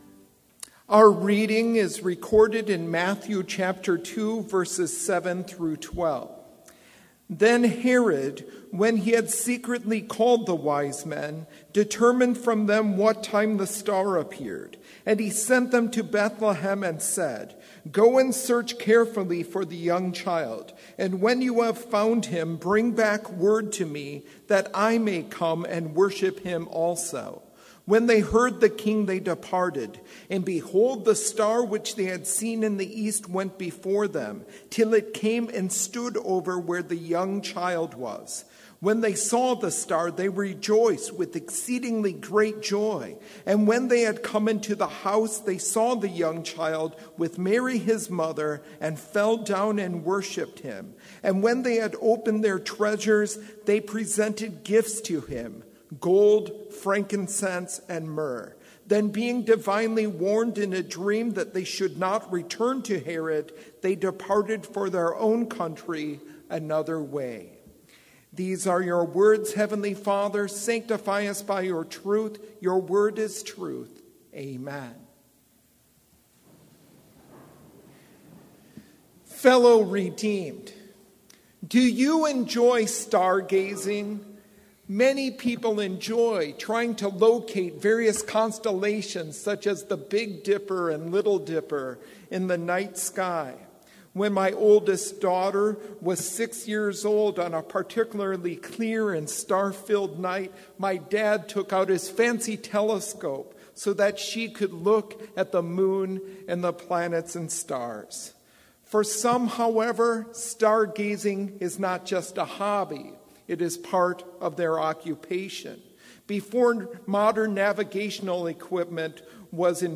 Sermon audio for Chapel - January 10, 2019